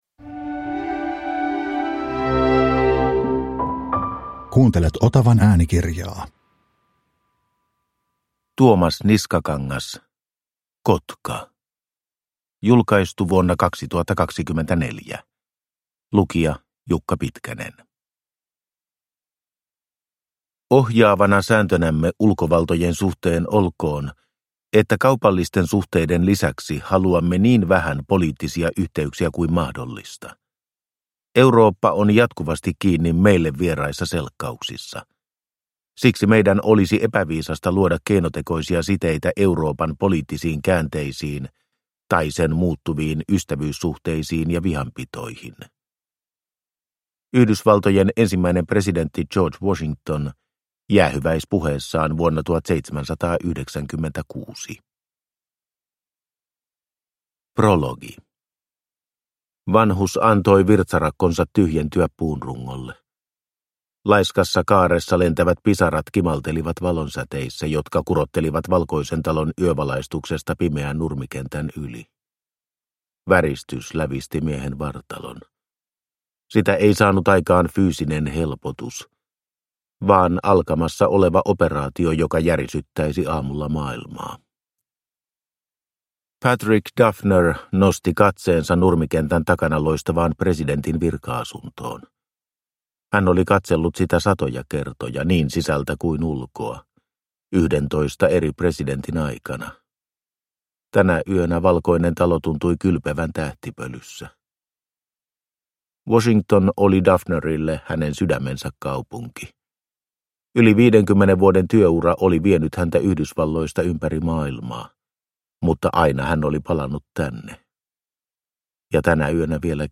Kotka – Ljudbok